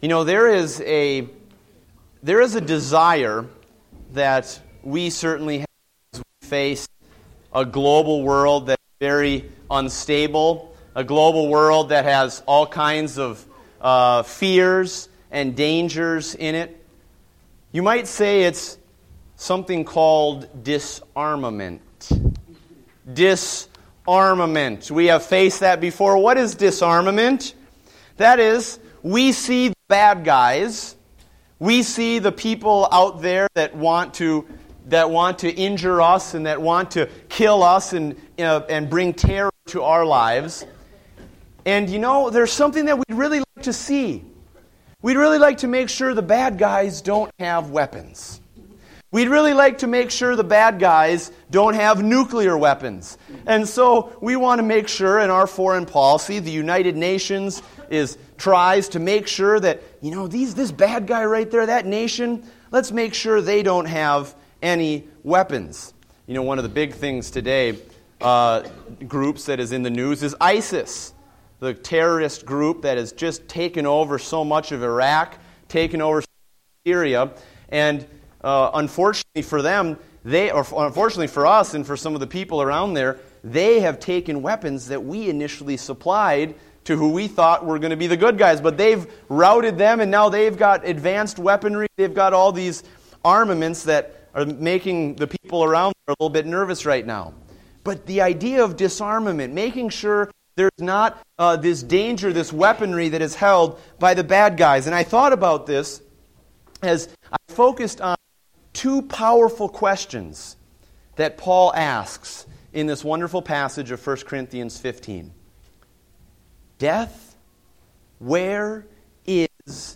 Date: October 19, 2014 (Adult Sunday School)